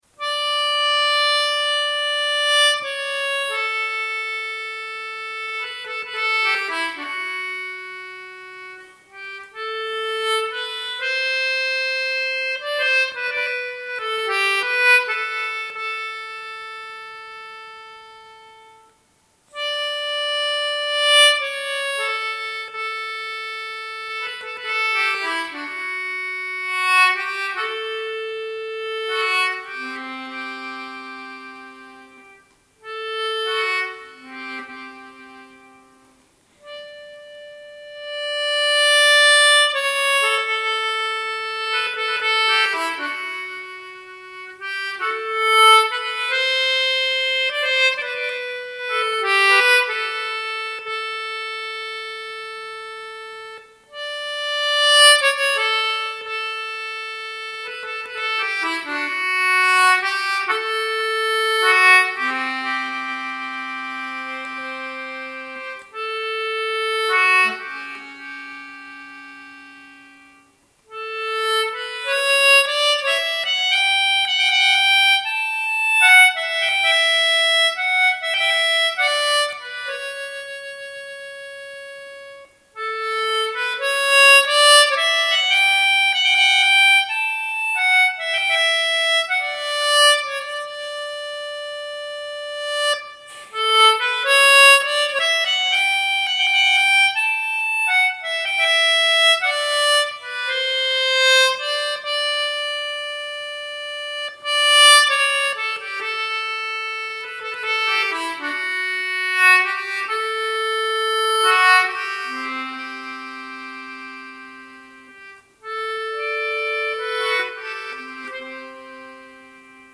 Port na bPúcaí (Music of the Fairies) is a haunted song if ever there was one.
My own rendition of Port na bPúcaí on the concertina –